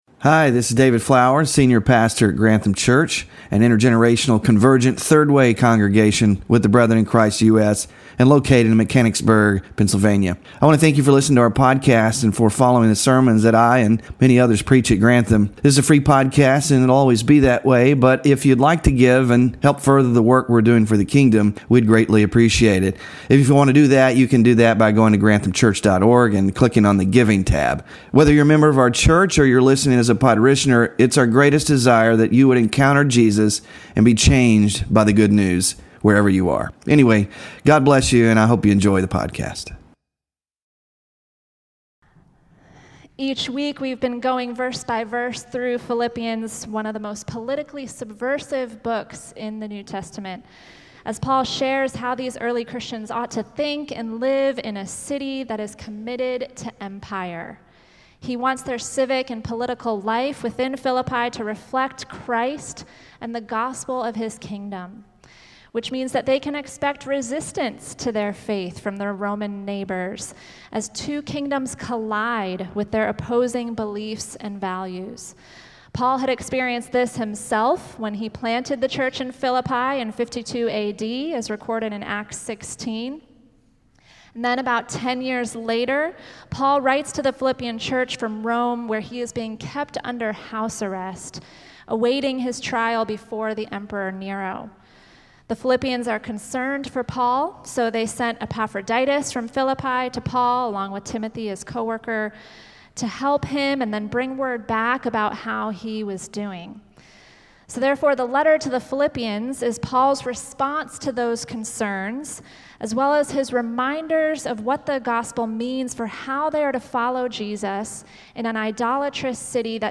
PHILIPPIANS SERMON SLIDES (7TH OF 8 IN SERIES) SMALL GROUP DISCUSSION QUESTIONS (9-22-24) BULLETIN (9-22-24)